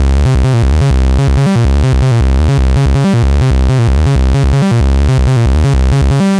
描述：一个苗条的硬核Arp。
标签： 150 bpm Hardcore Loops Bass Loops 1.08 MB wav Key : Unknown